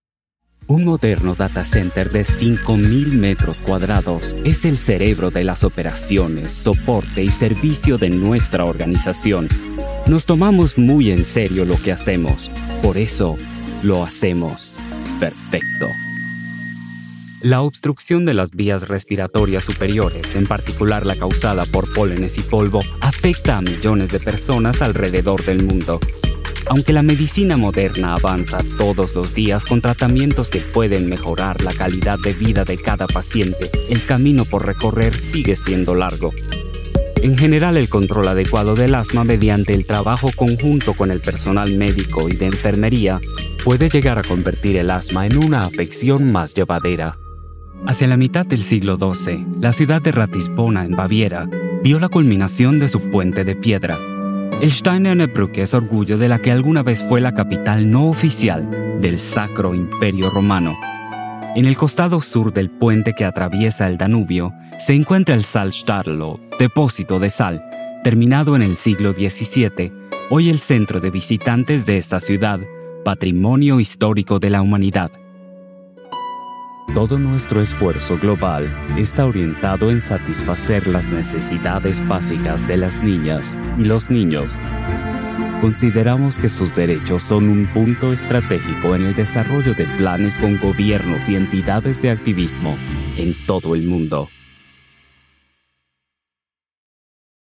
Male
Authoritative, Character, Confident, Friendly, Versatile
Voice reels
Microphone: Austrian Audio OC18, Shure SM7B, Austrian Audio CC8, t.bone RM 700